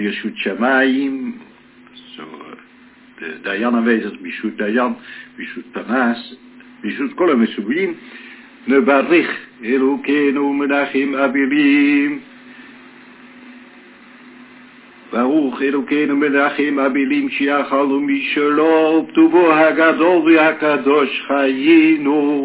Mitsva (funeral)
Chazzan: